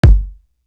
Real Mobb Do Kick.wav